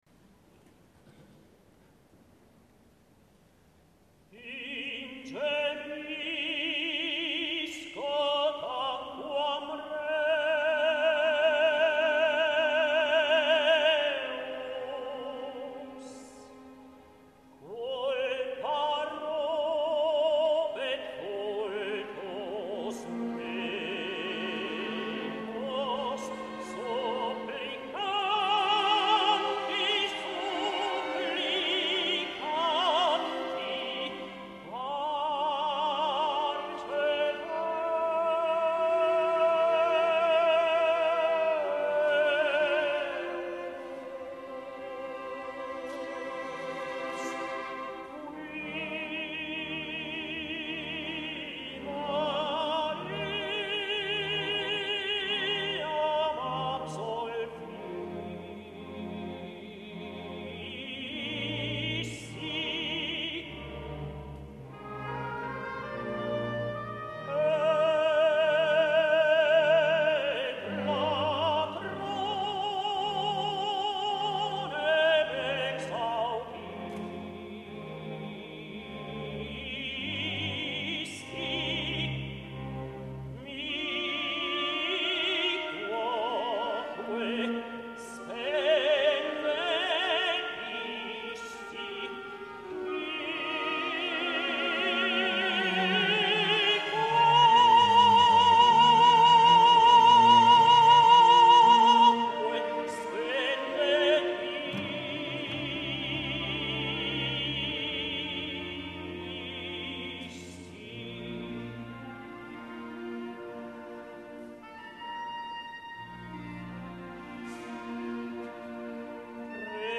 Tenore
Orchestra: Ensemble Ottavanota
Registrazione dal vivo 18/01/2004